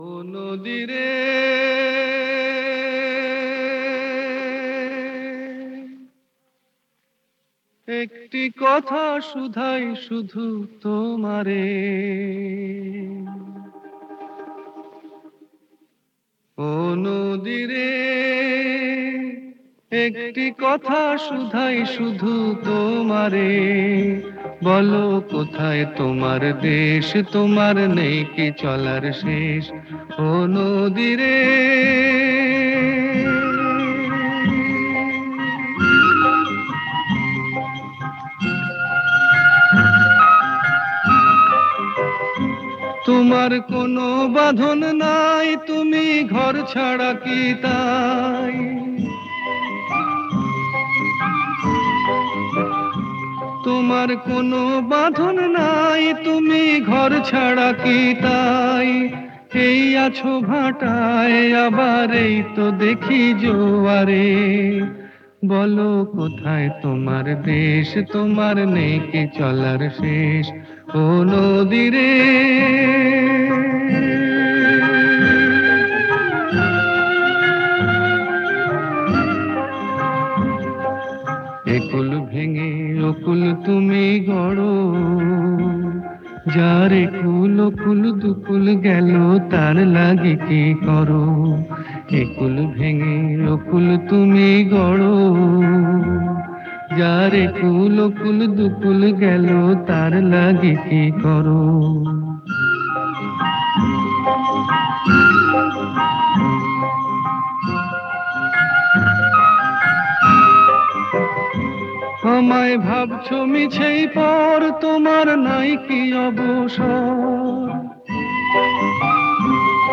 Bengali song